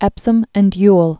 (ĕpsəm; yəl)